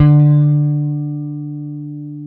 Index of /90_sSampleCDs/AKAI S-Series CD-ROM Sound Library VOL-7/JAZZY GUITAR
JAZZ GT1D2.wav